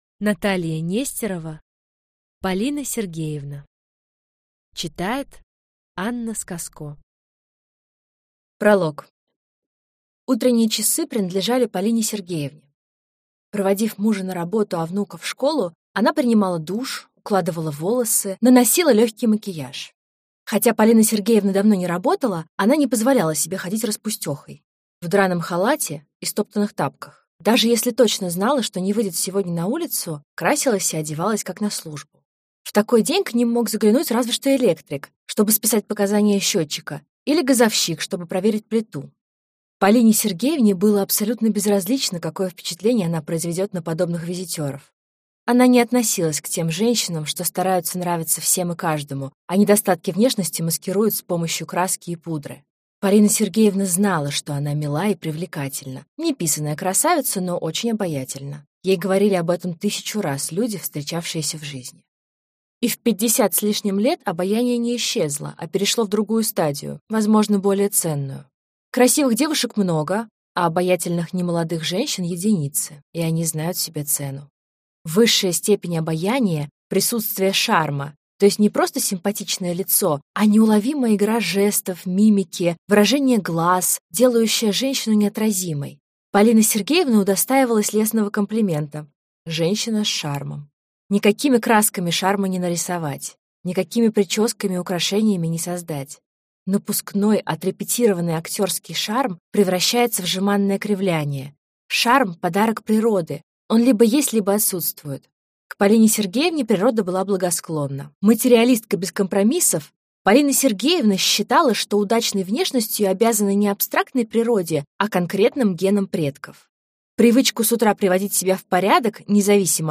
Аудиокнига Полина Сергеевна - купить, скачать и слушать онлайн | КнигоПоиск